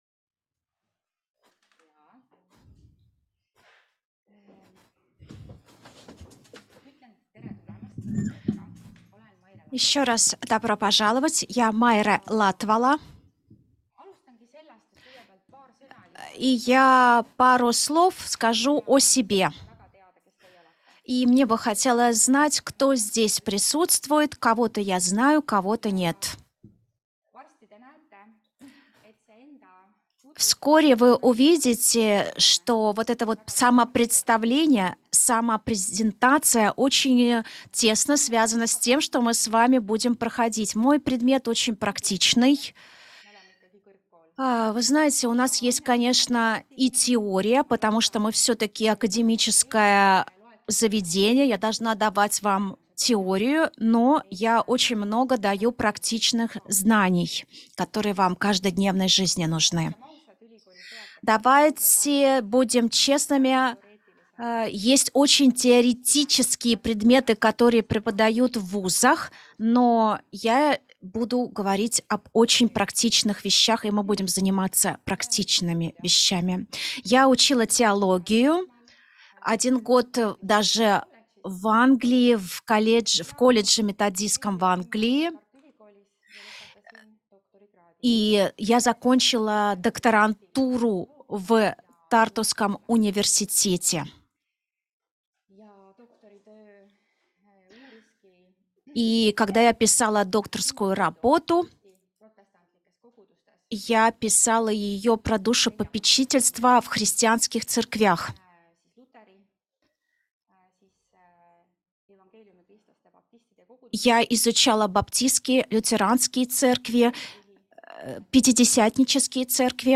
Семейное консультирование. 1. лекция [RU] – EMKTS õppevaramu